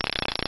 reel.wav